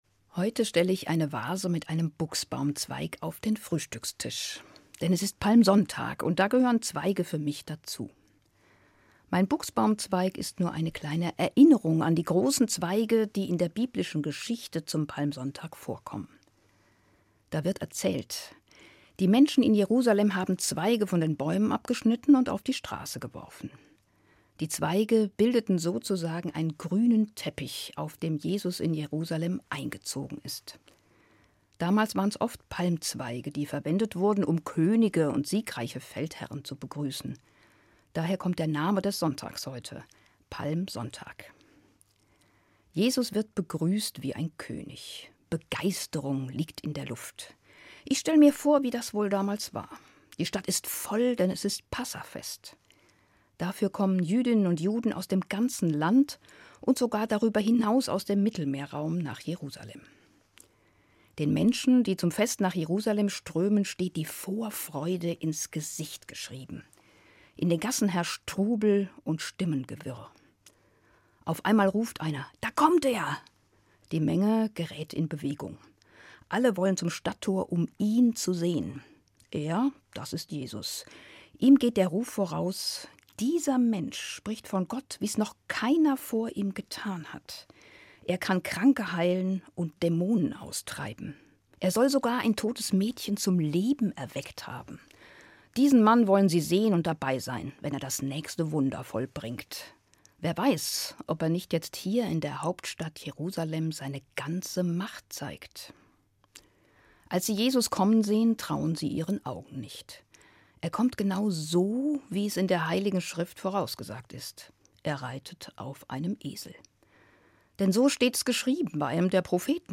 Evangelische Pfarrerin